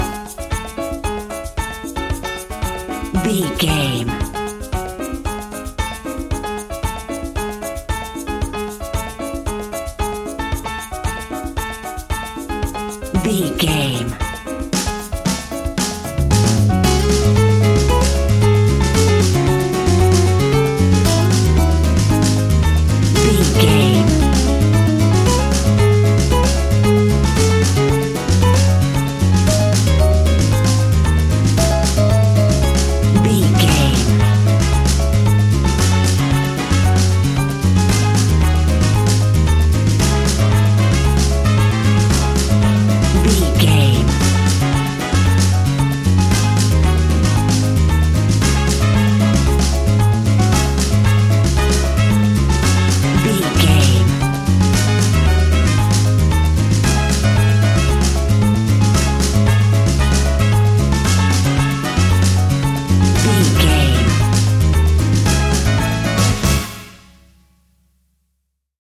An exotic and colorful piece of Espanic and Latin music.
Uplifting
Aeolian/Minor
C#
maracas
percussion spanish guitar